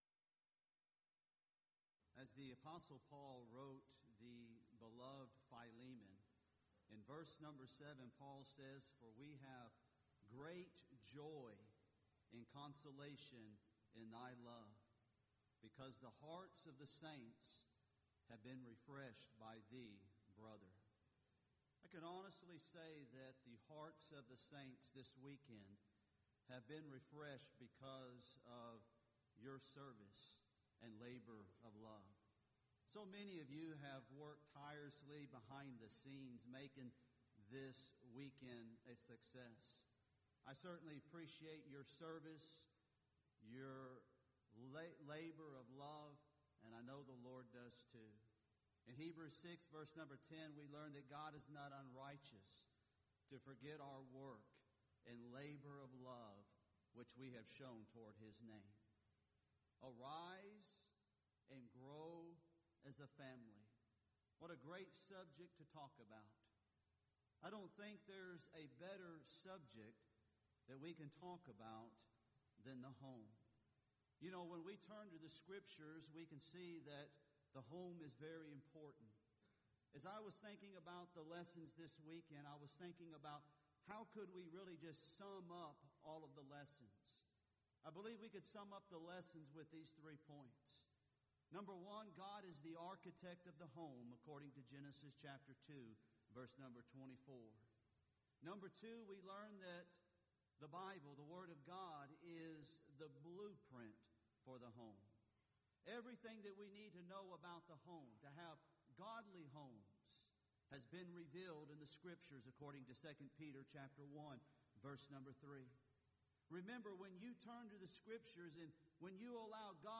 Event: 3rd Annual Southwest Spritual Growth Workshop
lecture